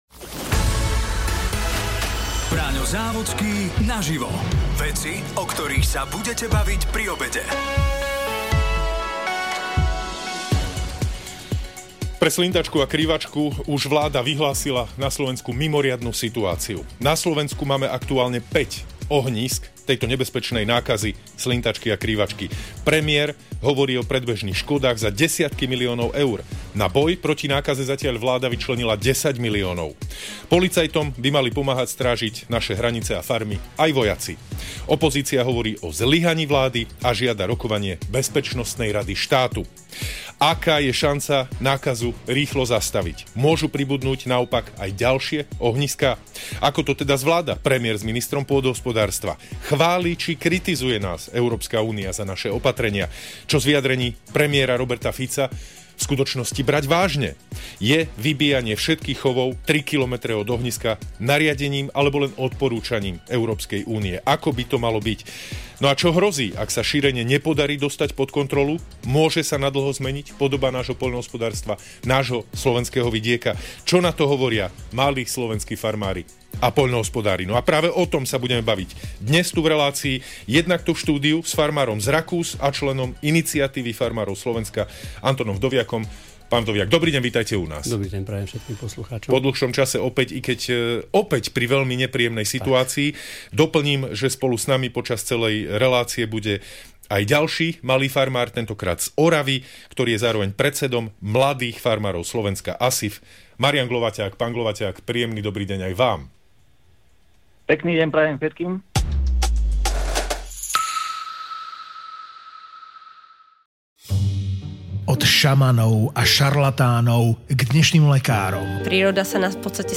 Pobavíme sa s farmárom